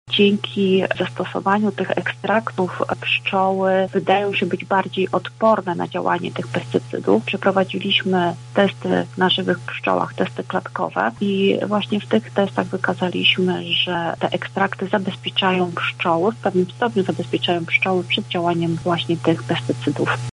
-mówi doktor habilitowana